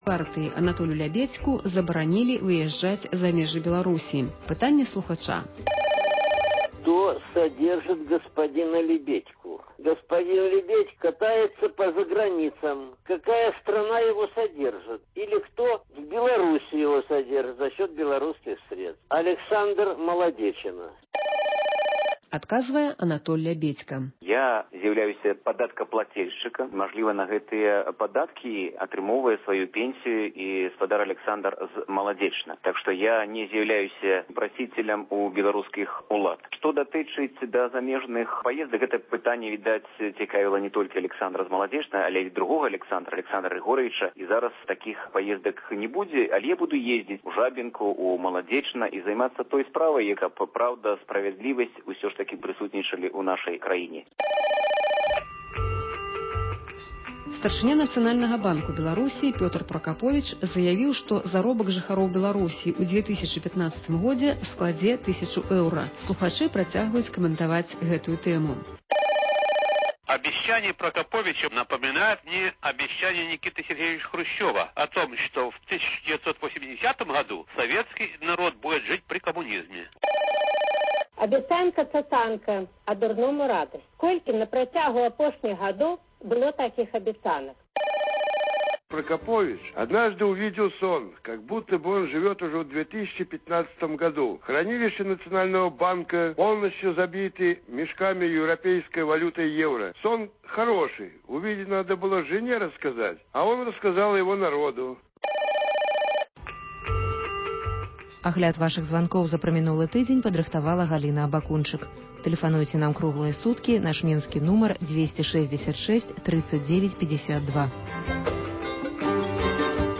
гутарыць з выдаўцом